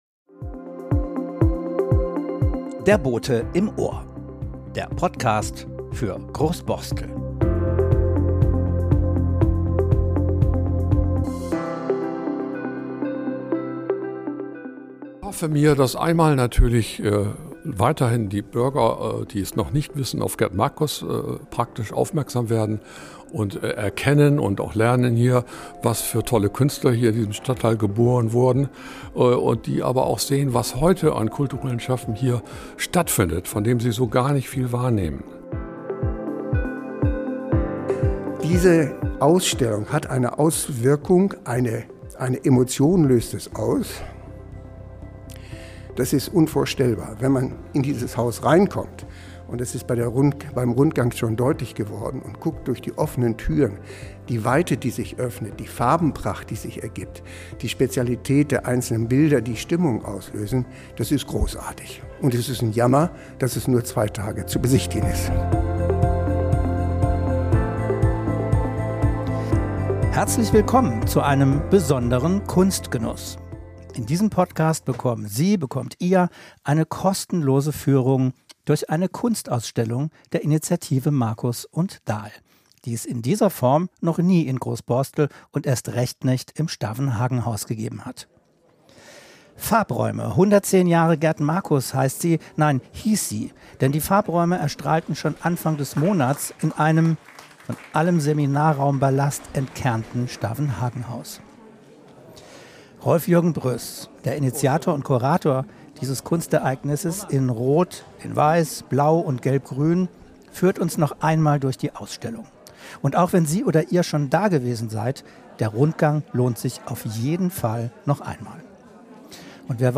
#45 Eine exklusive Kunstführung: Farb-Räume im Stavenhagenhaus ~ Der Bote im Ohr Podcast